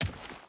Footsteps / Dirt
Dirt Run 4.wav